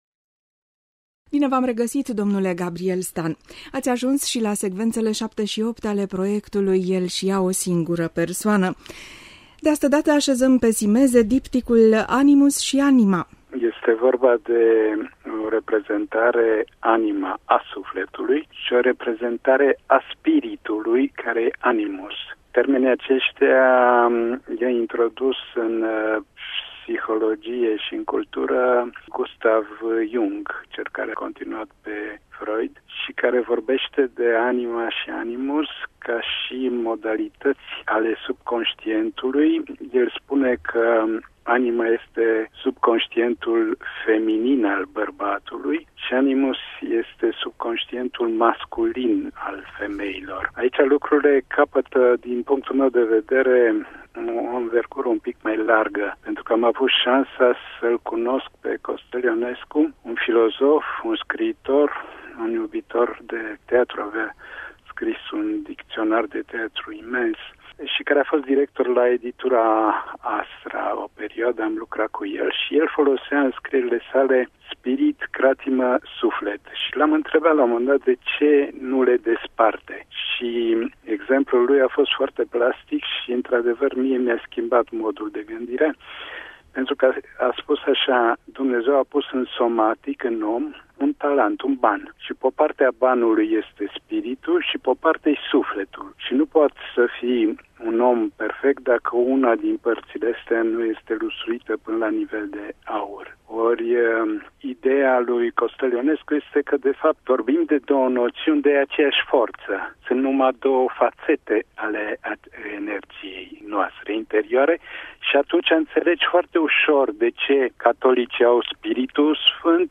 Maestrul explicã